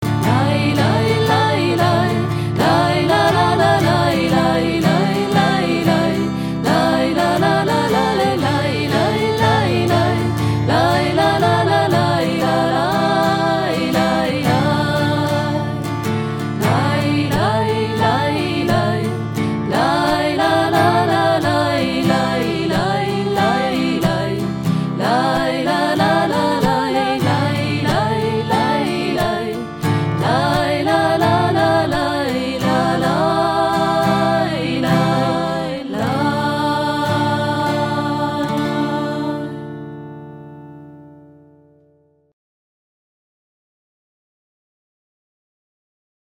Jüdisch – Chassidisch